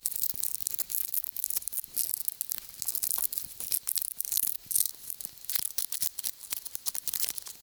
Звуки тли
Звук тли, поселившейся на кустарниках